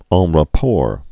ɴ rə-pôr, ră-)